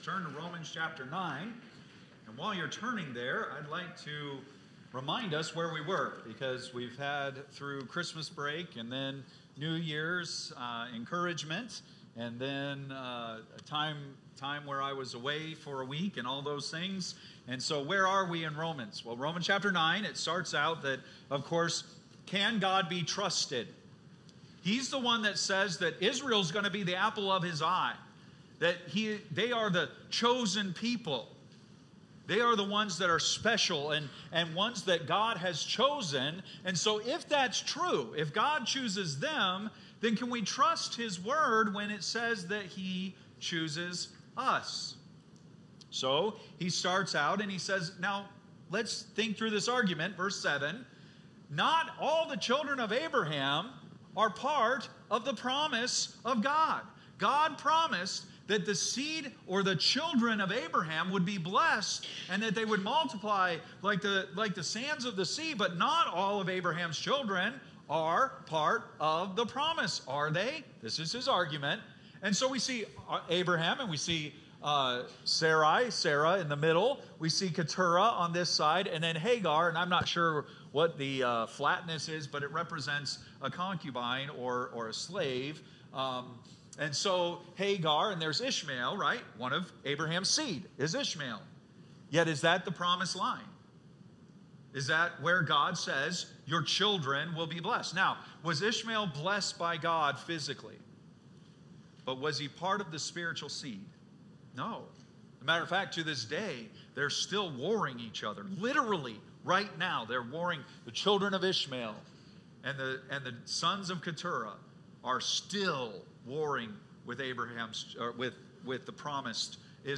Date: January 12, 2025 (Sunday Morning)